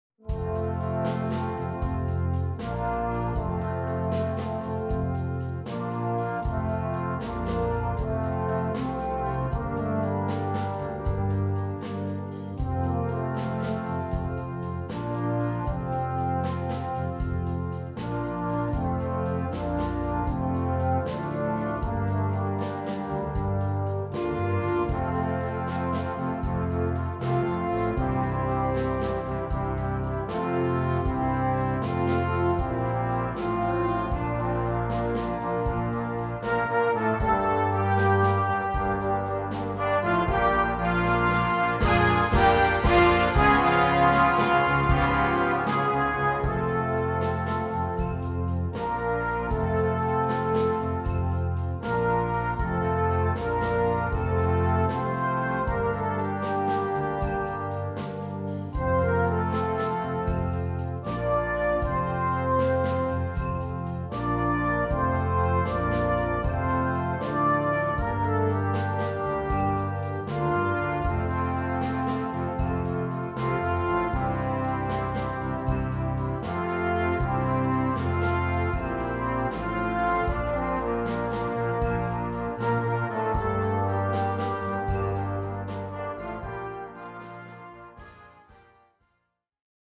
Gattung: Weihnachtsmusik für Blasorchester
Besetzung: Blasorchester